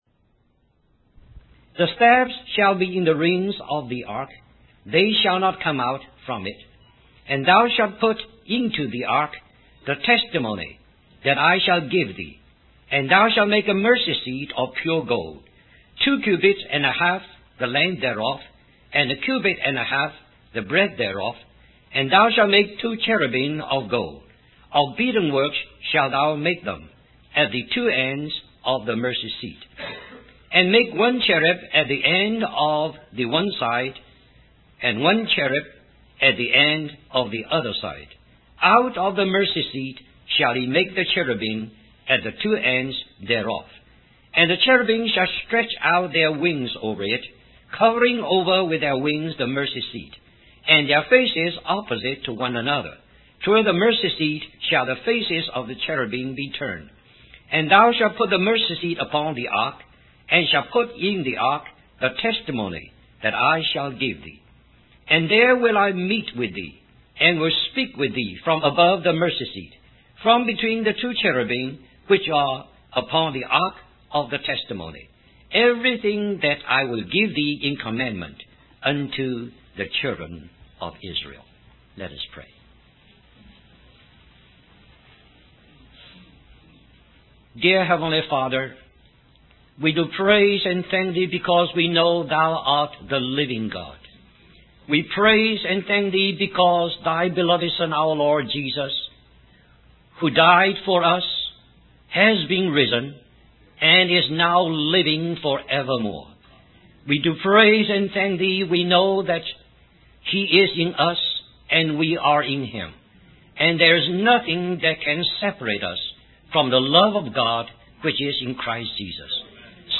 In this sermon, the speaker discusses the spiritual experience of believers after they have accepted Jesus as their savior.